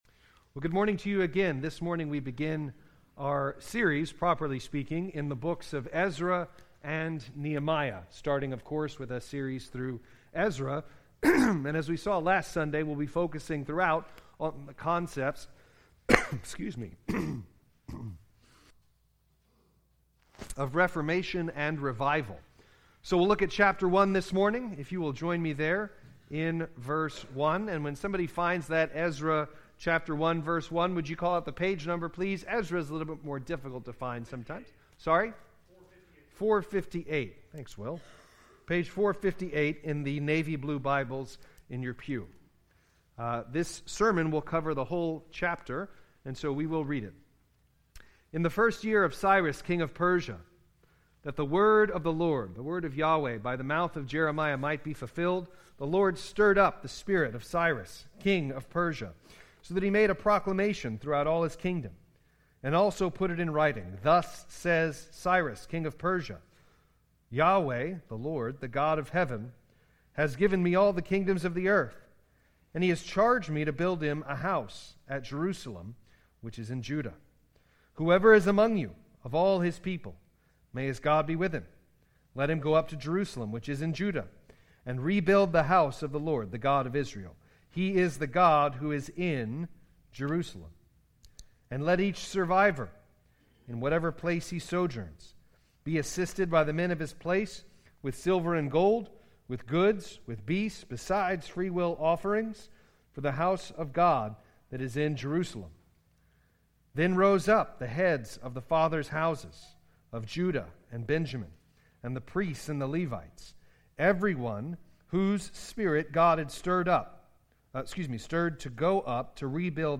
We continue our sermon series this morning in the Apostles’ Creed.